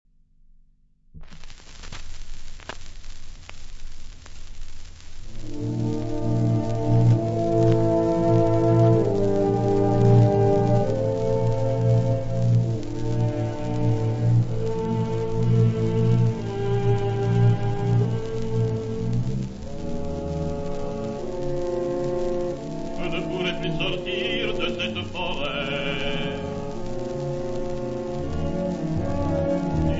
• Registrazione sonora musicale